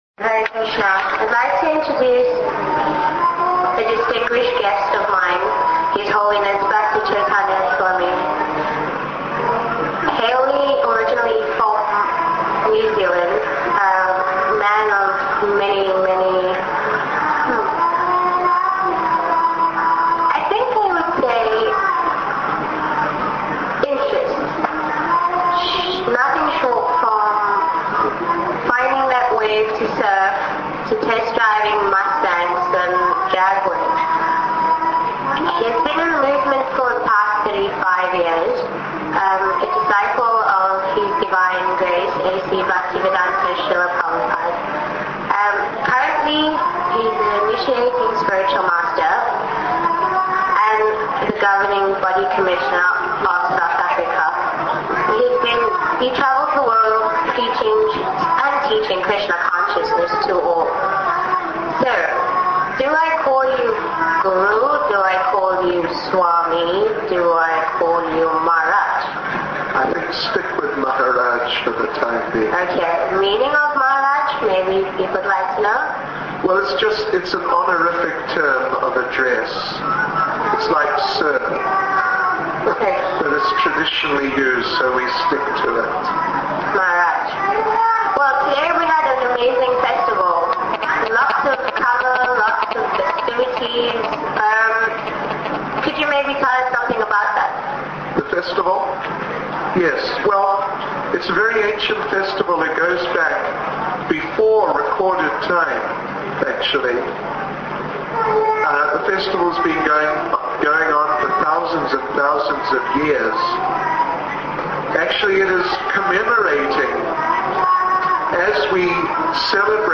Cape Town Ratha Yatra Interview 1
cape-town-ratha-yatra-interview